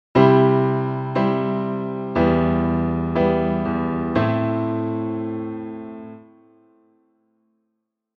In the first example the progression from Em to Am is relatively neutral. We expect it to keep sauntering along.
1_C-Em-Am.mp3